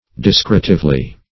Discretively \Dis*cre"tive*ly\